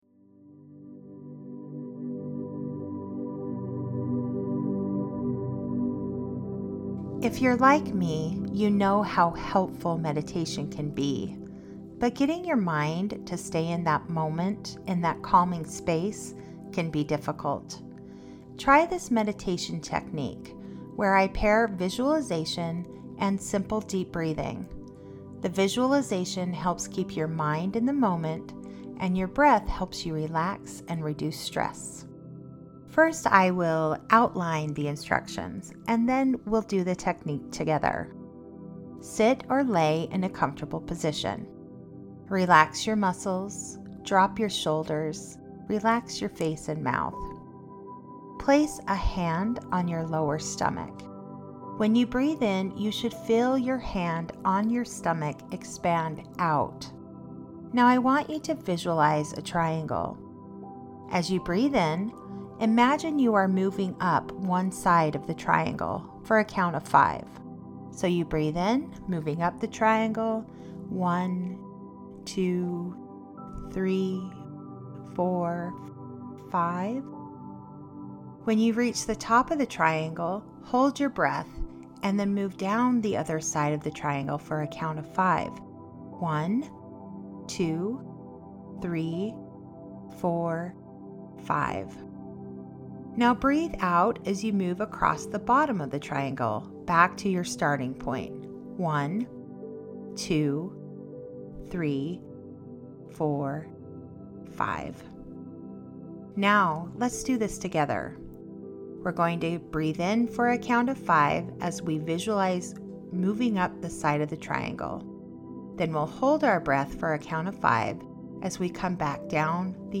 Mindful meditation
MHFitnessMindfulMeditation.mp3